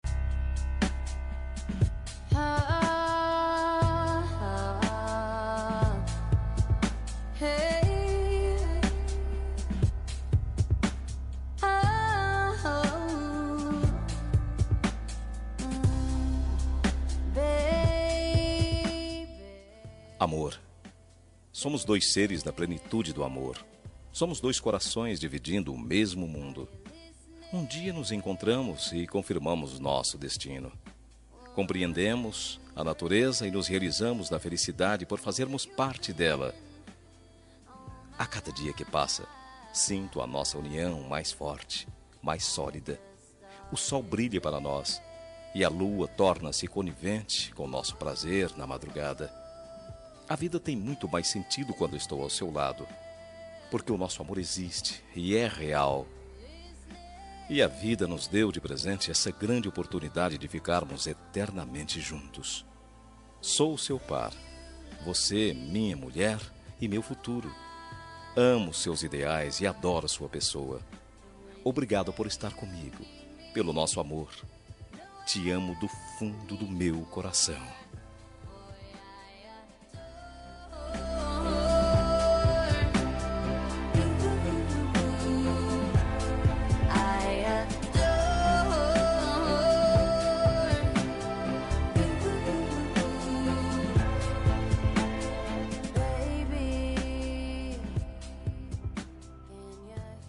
Telemensagem Romântica para Esposa – Voz Masculina – Cód: 5203